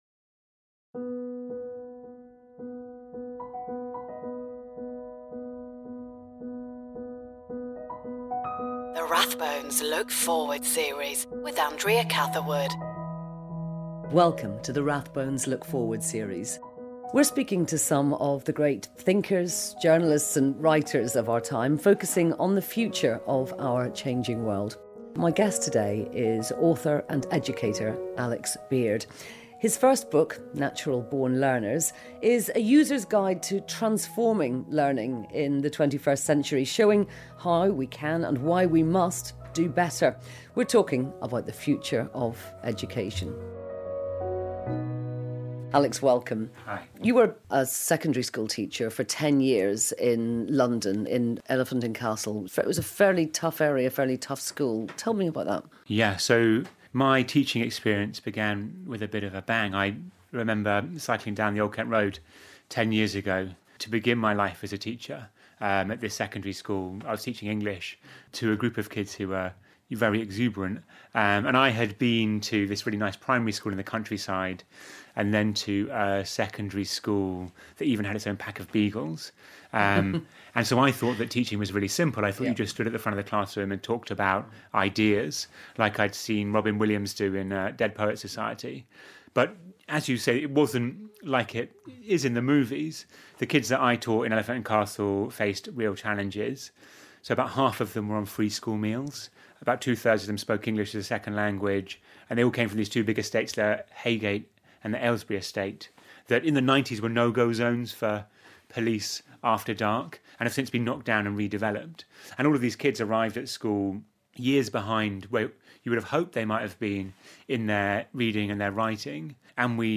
Facebook Twitter Headliner Embed Embed Code See more options Rathbones Look forward series with Andrea Catherwood Presenter Andrea Catherwood in conversation with some of the great thinkers and writers of our time, in an 8 part series focusing on the future of our changing world.